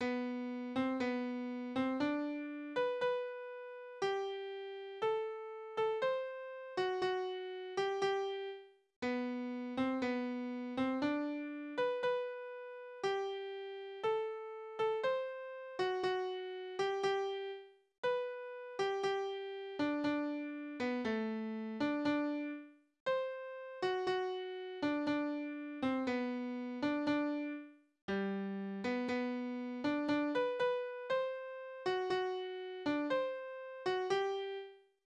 Tonart: G-Dur
Taktart: 2/4
Tonumfang: Oktave, Quarte